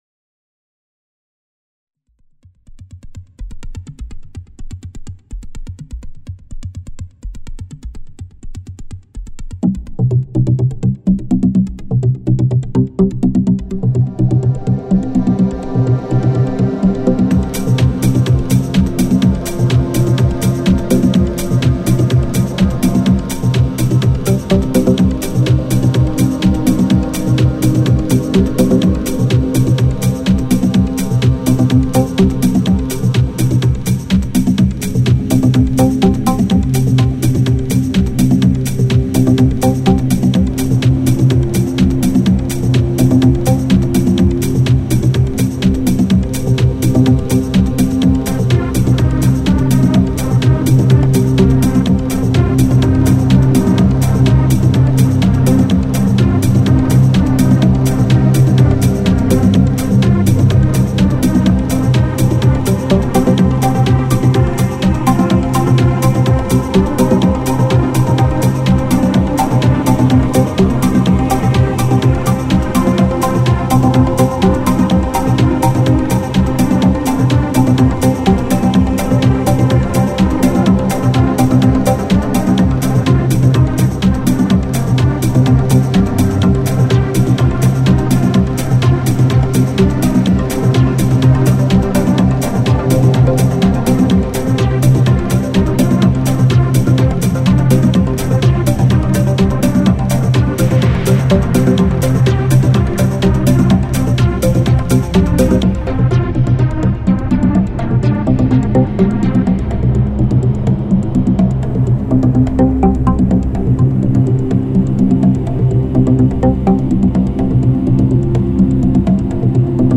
Allegro [120-130] joie - synthetiseur - nuit - gym - sport